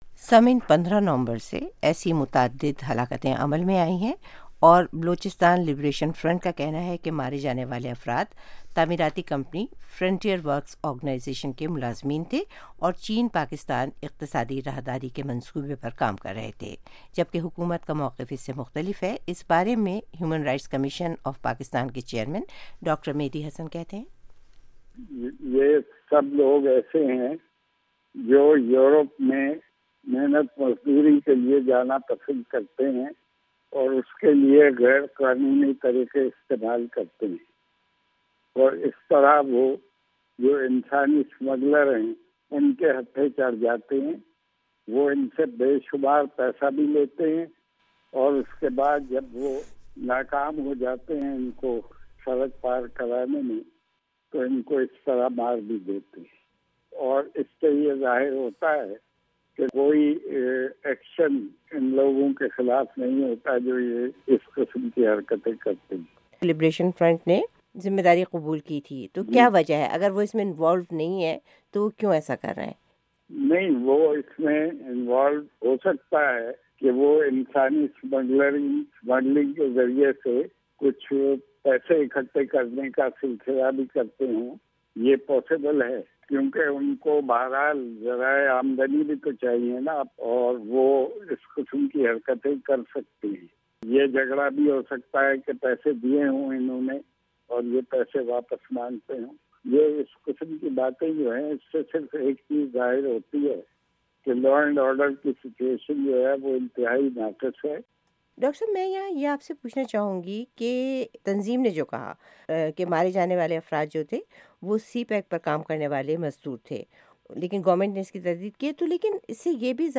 بلوچستان میں ہلاکتوں کے حالیہ واقعات: رپورٹ